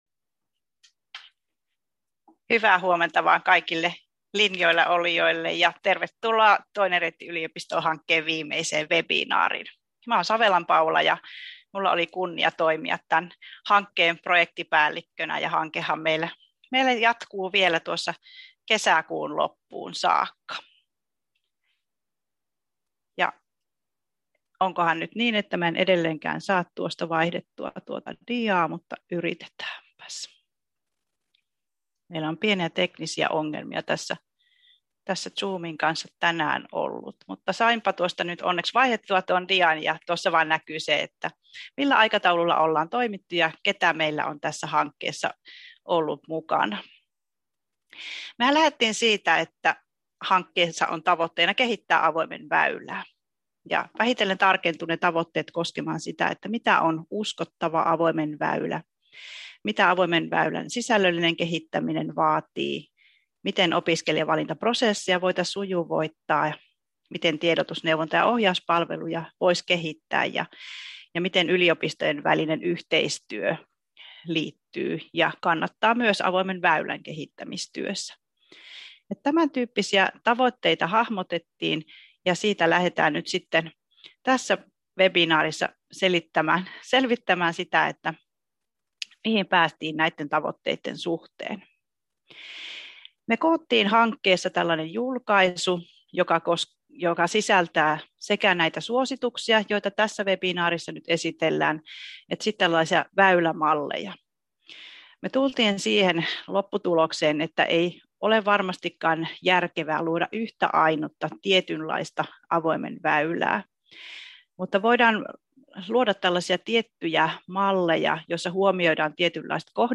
TRY-webinaari 14.4.2021 — Moniviestin